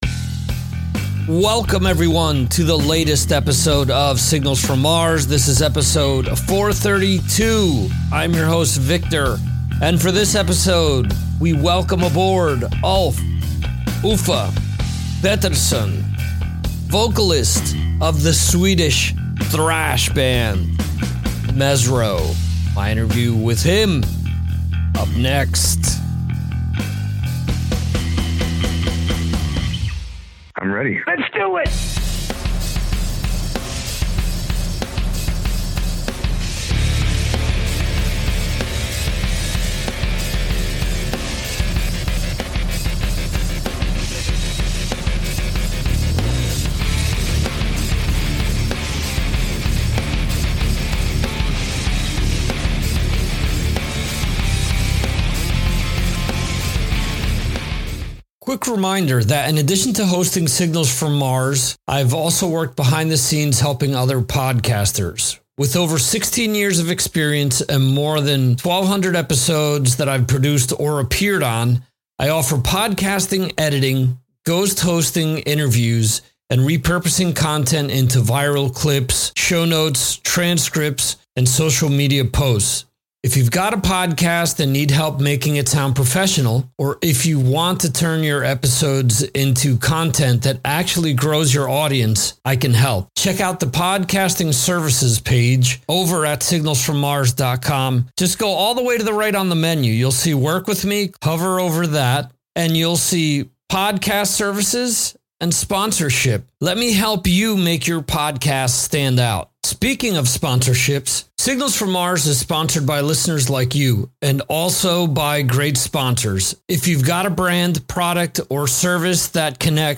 All types of hard rock and metal interviews and music discussions since 2009.